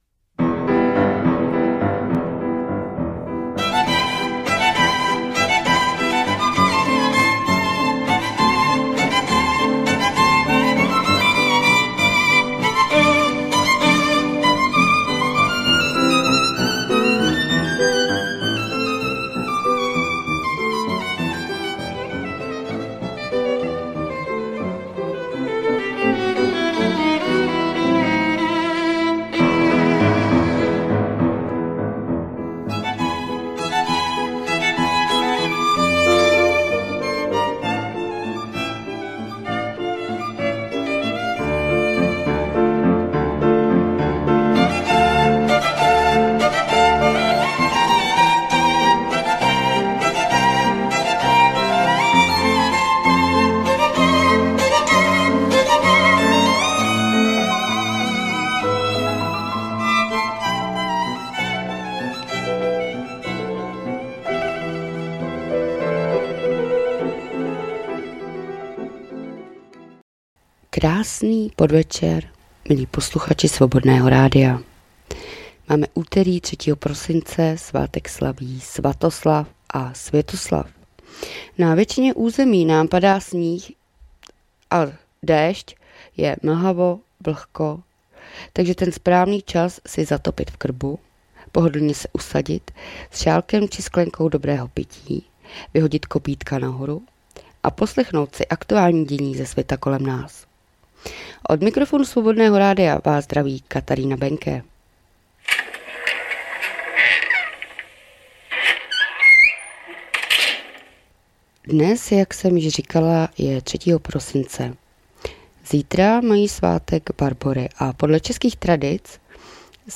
2024-12-03 - Zpravodajský přehled.